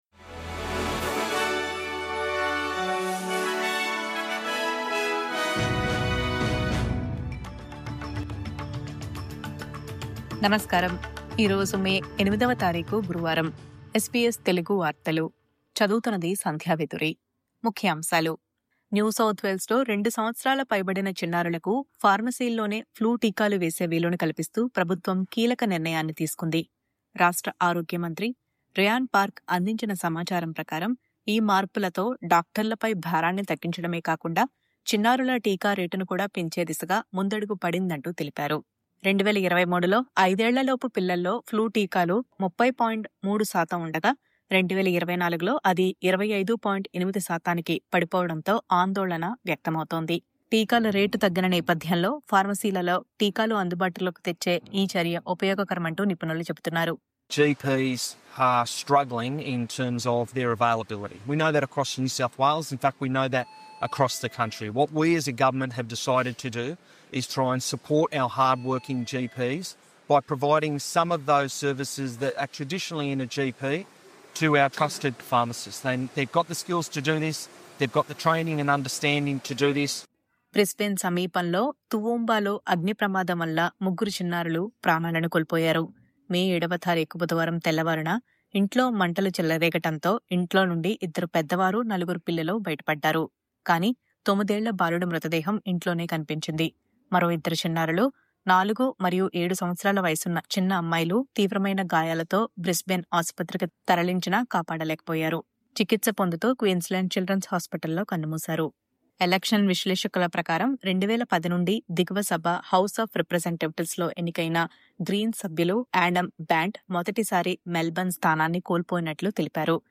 SBS తెలుగు వార్తలు..